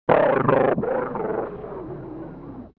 دانلود آهنگ رادیو 15 از افکت صوتی اشیاء
جلوه های صوتی
دانلود صدای رادیو 15 از ساعد نیوز با لینک مستقیم و کیفیت بالا